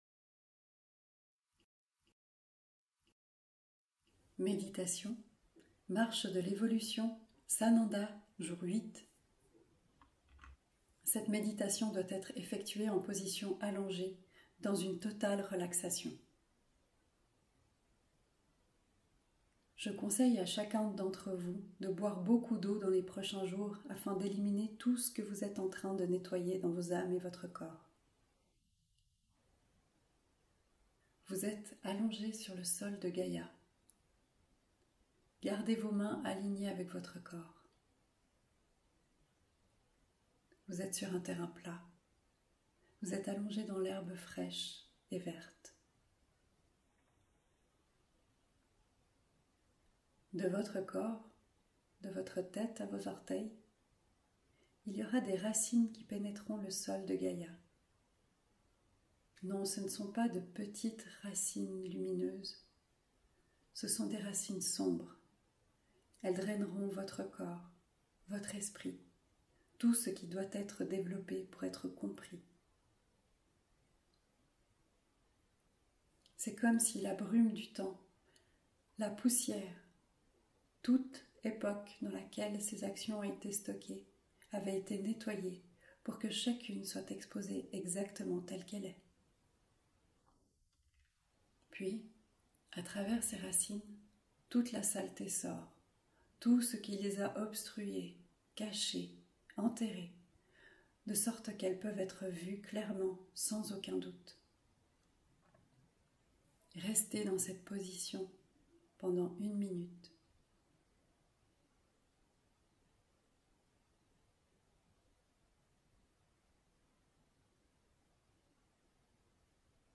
Méditation - sans_pub